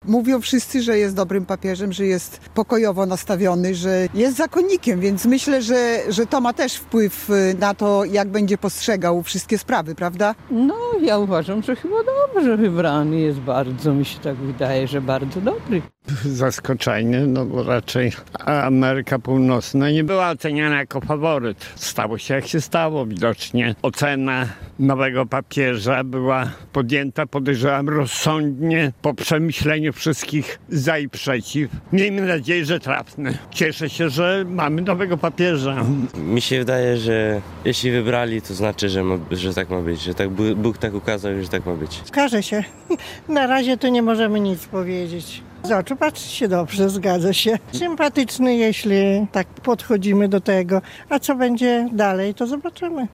Zapytaliśmy przechodniów łomżyńskich ulic co sądzą o kolejnym biskupie Rzymu, który przyjął imię Leon XIV: